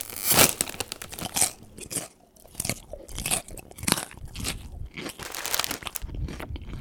action_eat_chips_1.ogg